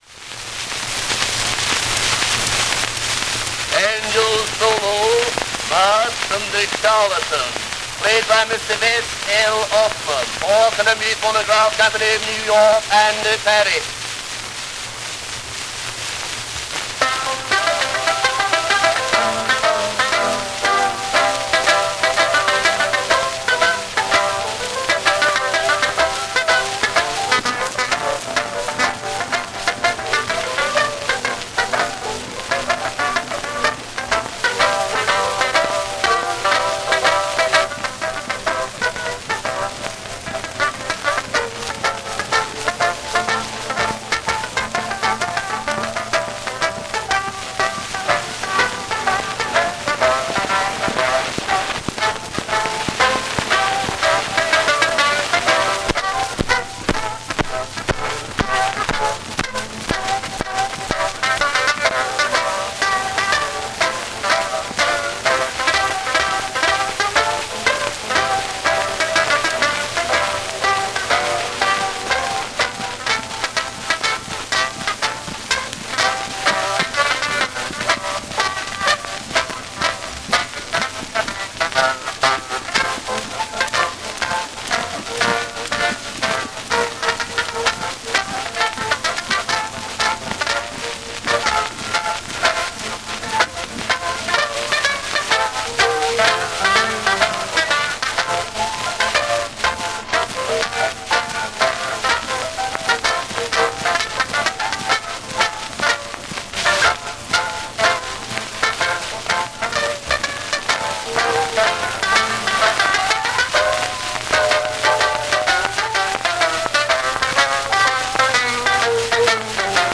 Banjo solo by Vess L. Ossman ; with piano accompaniment.
Brown wax cylinder.
Banjo music.